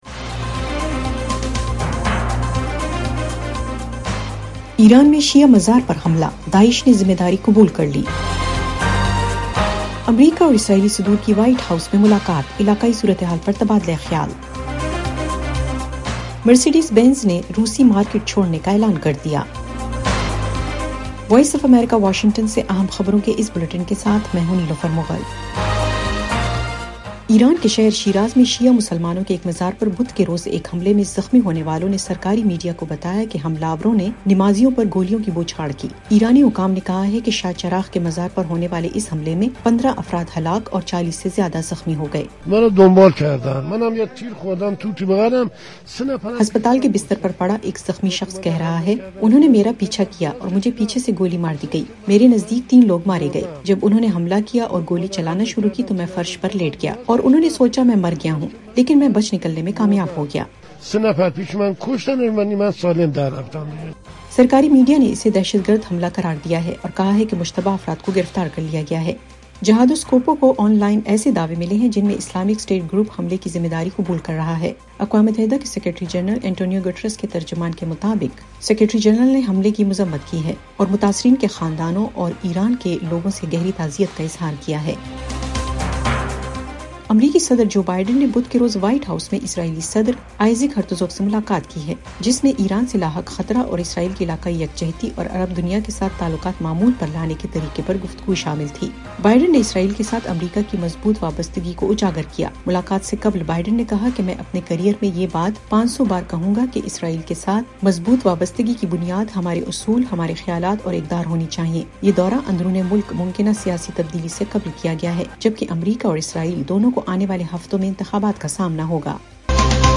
ایف ایم ریڈیو نیوز بلیٹن: شام 6 بجے